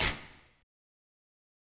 Snares / Walking Snare